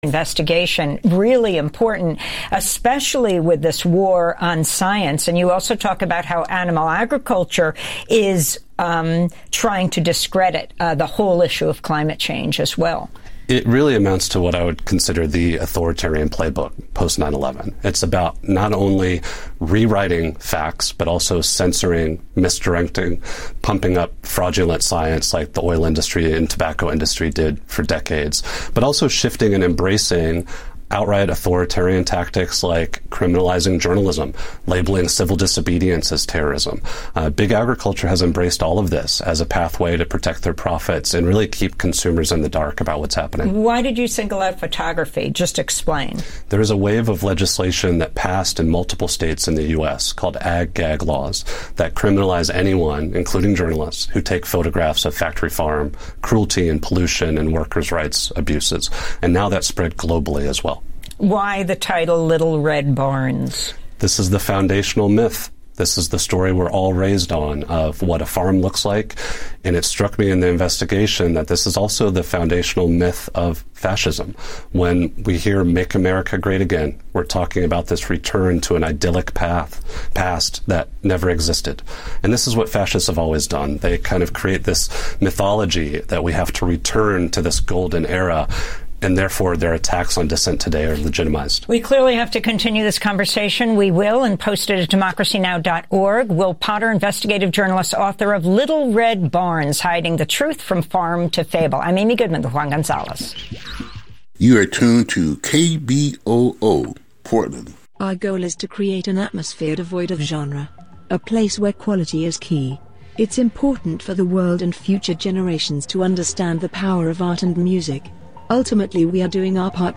Hosted by: KBOO News Team
Non-corporate, community-powered, local, national and international news